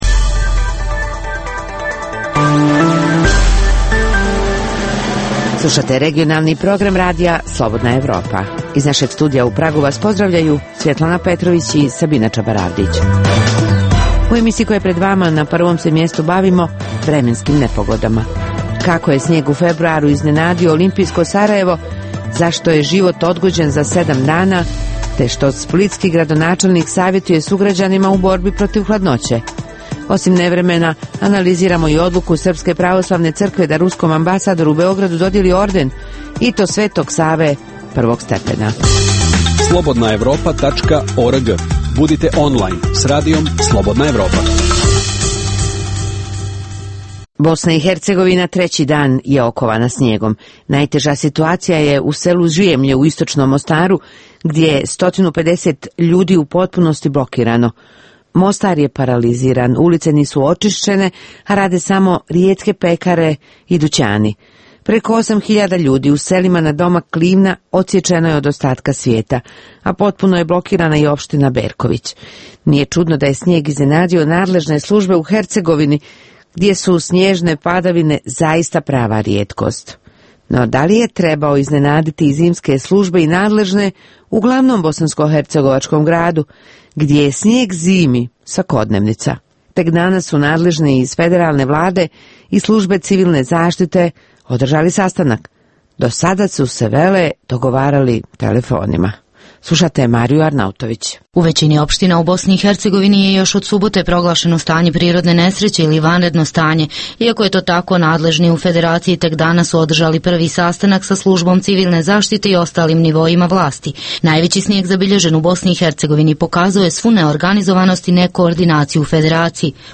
Reportaže iz svakodnevnog života ljudi su takođe sastavni dio “Dokumenata dana”.